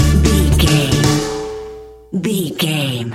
Aeolian/Minor
flamenco
maracas
percussion spanish guitar